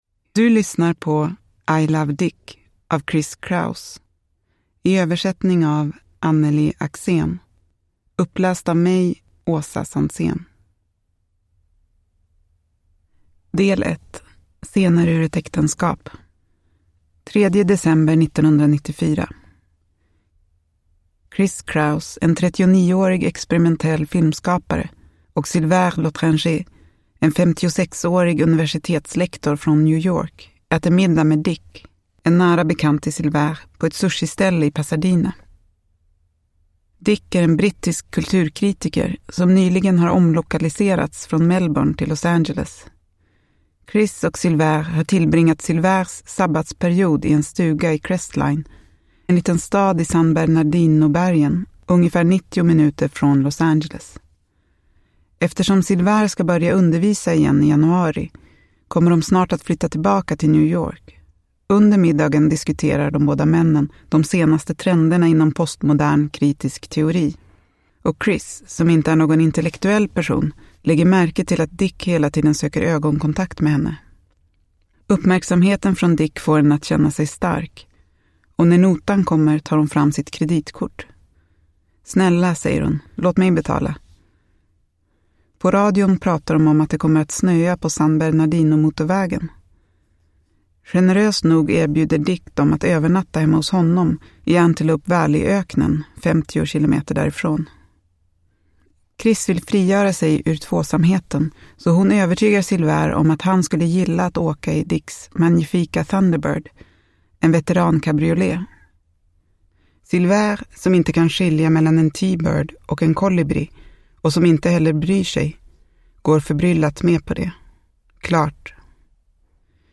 I Love Dick (ljudbok) av Chris Kraus